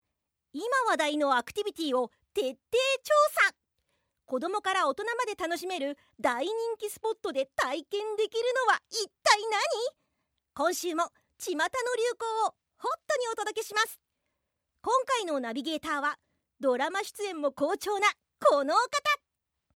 ナレーション　TV番組